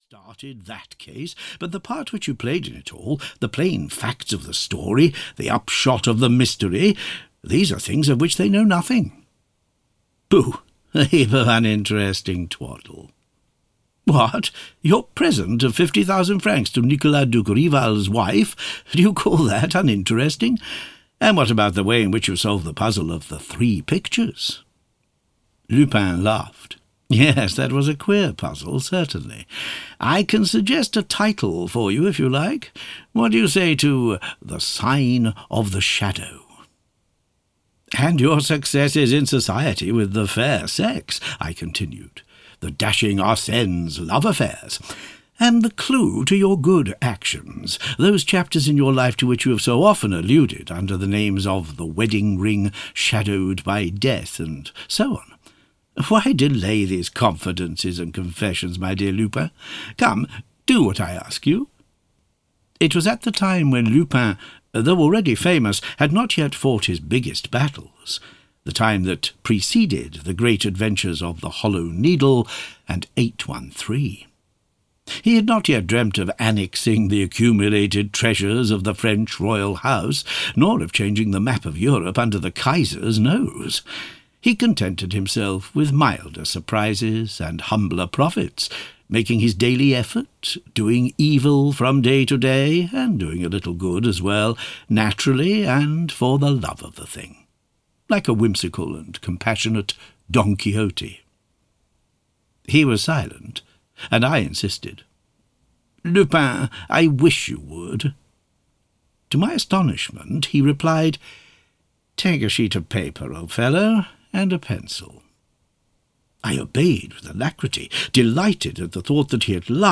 The Confessions of Arsène Lupin (EN) audiokniha
Ukázka z knihy